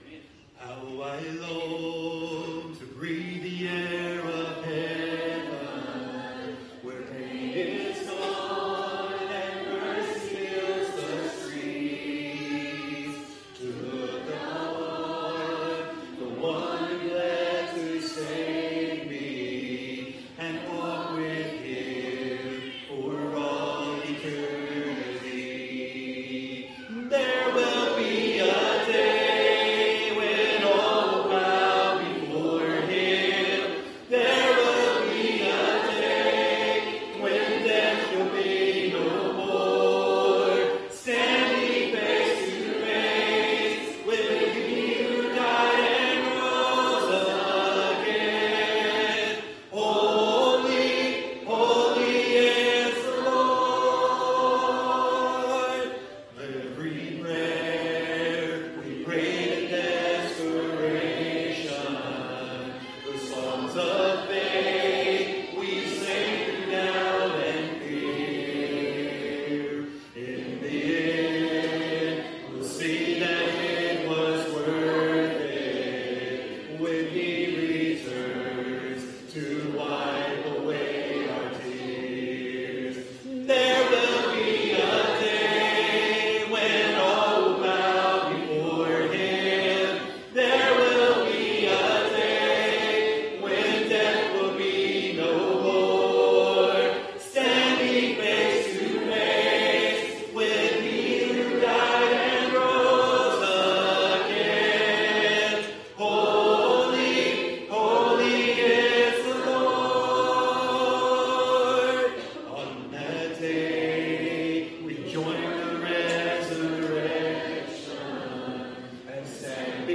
A Night of Worship